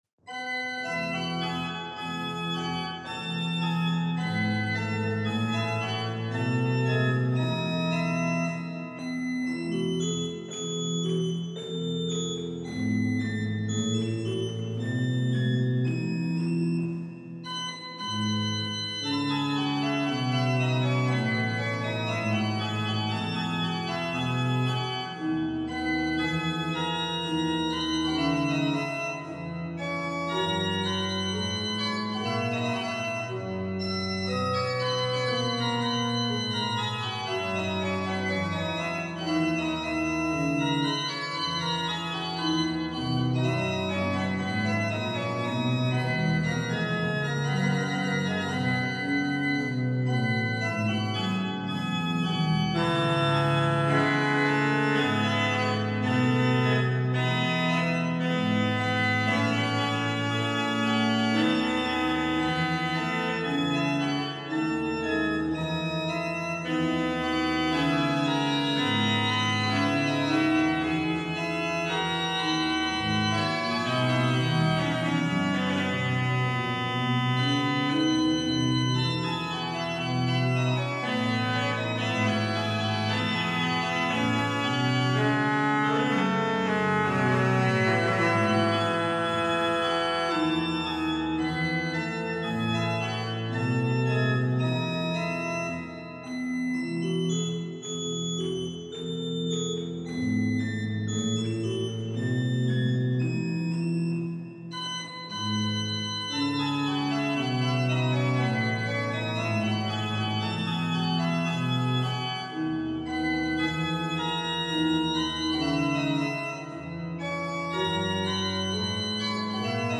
main organ, Freiberg Cathedral